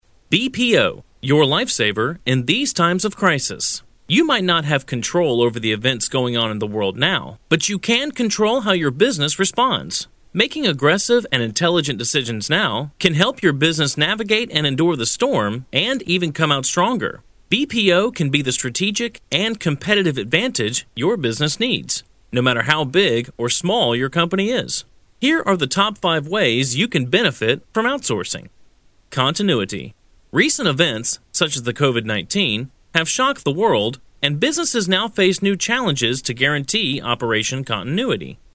男英1 - 声优配音_声优在线配音_声优配音价格_找声优 - voice666配音网
男英1 美式英语 广告 激情 欢快 洪亮 激情激昂|积极向上|时尚活力|亲切甜美|素人
男英1 美式英语 年轻 清晰 产品介绍 娓娓道来|积极向上|时尚活力|亲切甜美